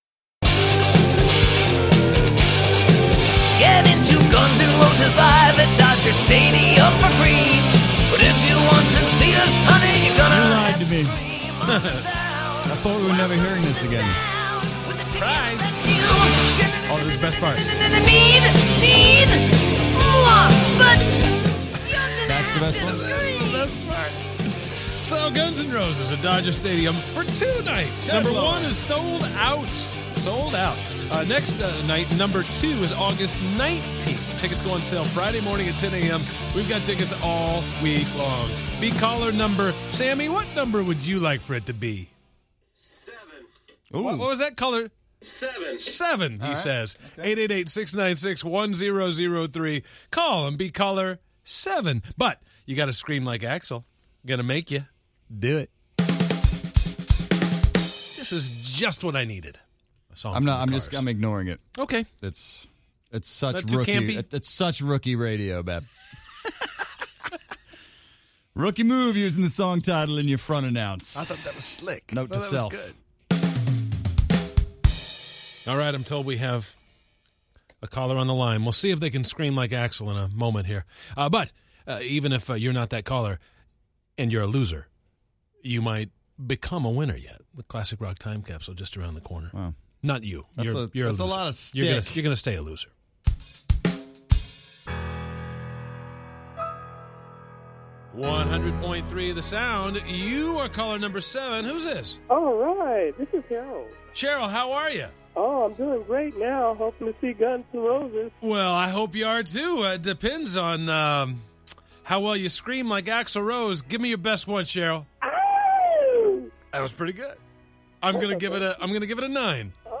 we receive a call from a concerned environmentalist